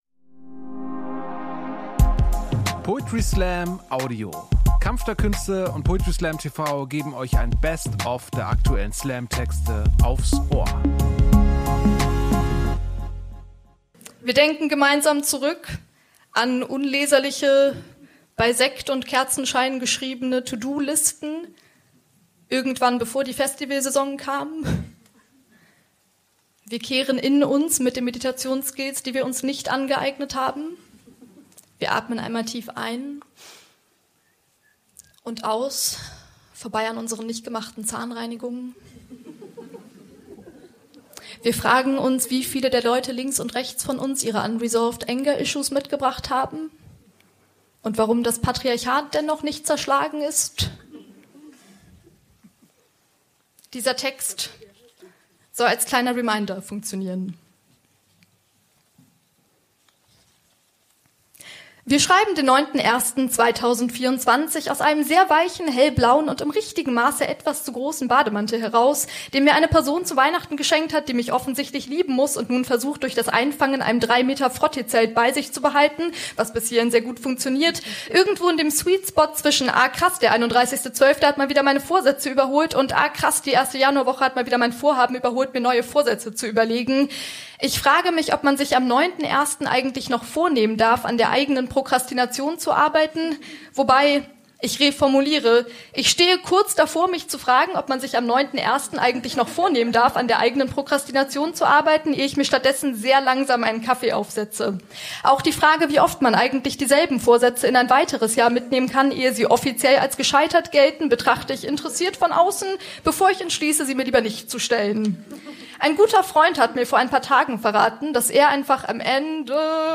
Stage: Ernst Deutsch Theater, Hamburg
Exquisite Poetry Slam Liveshows